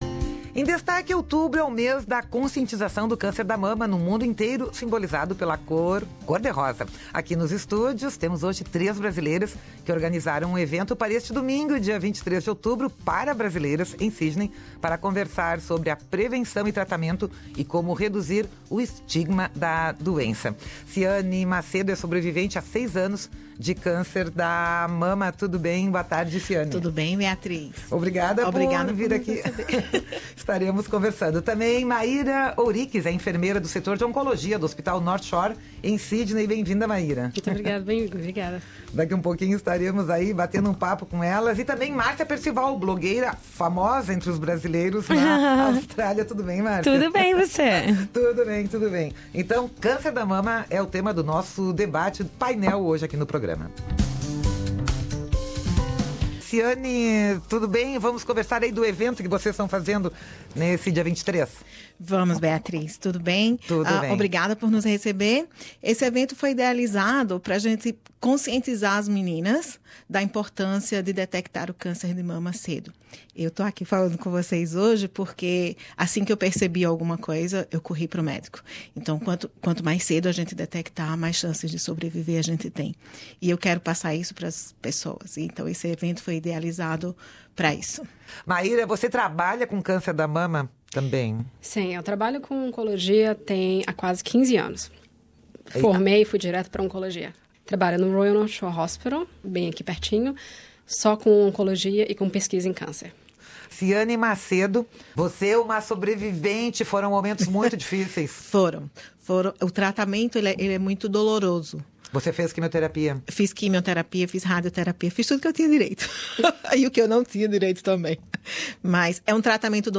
conversam sobre a doença nos estúdios da Rádio SBS.